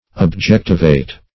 Objectivate \Ob*jec"ti*vate\